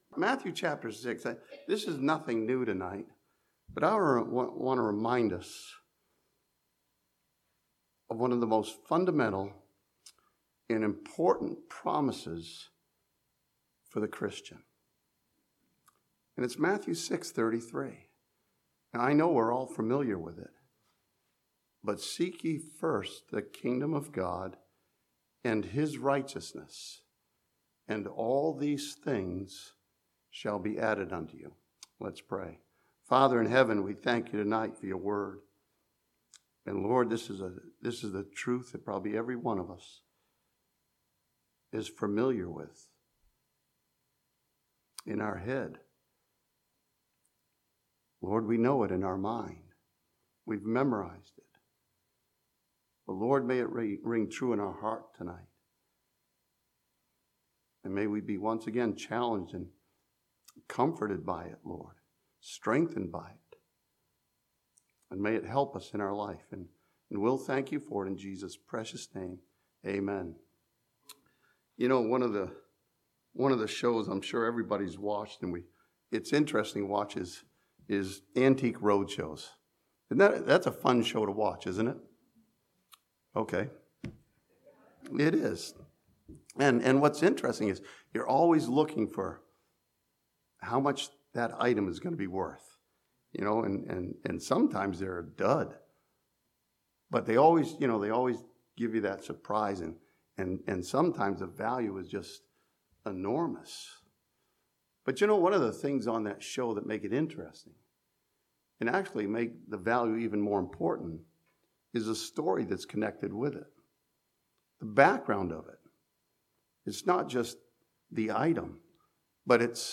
This sermon from Matthew 6:33 reminds each of us of the simple but valuable truth that God cares for you.